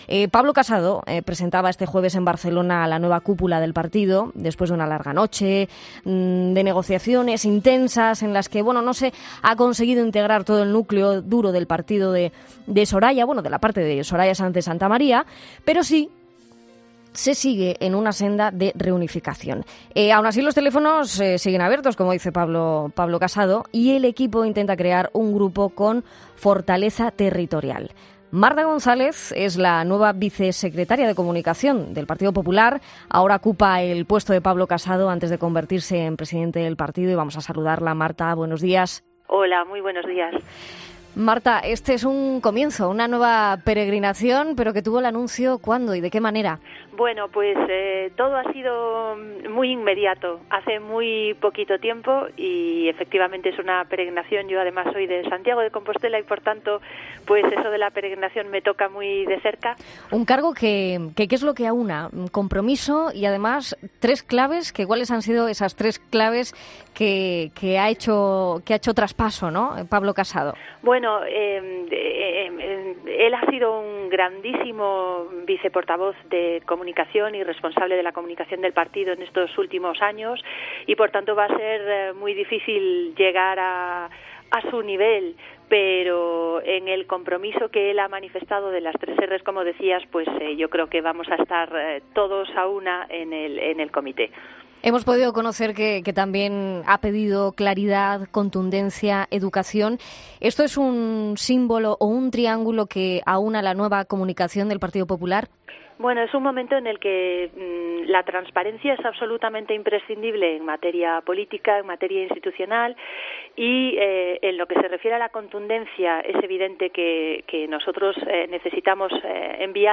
Para conocerla mejor, este domingo ha sido entrevistada en 'Fin de Semana', donde ha dicho que su antecesor fue “un grandísimo” portavoz, por lo que tiene un reto importante por delante.